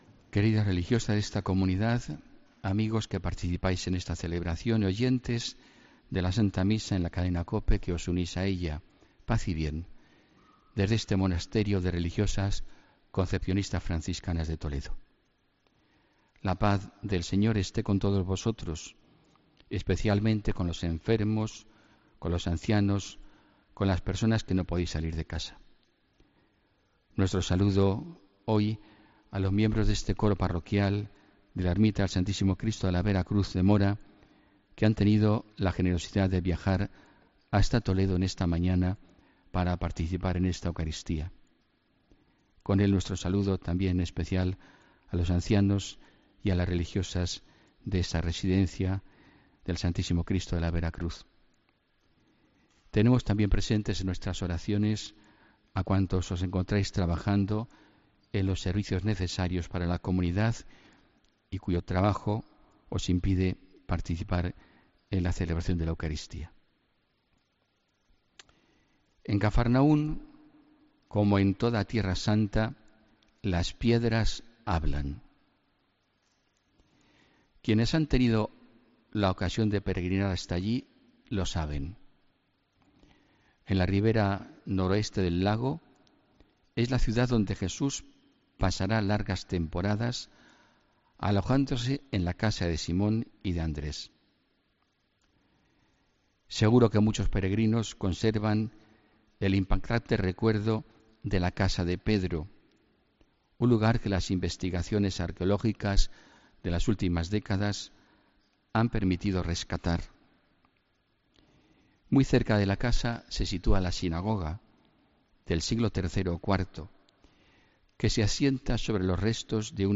HOMILÍA 28 ENERO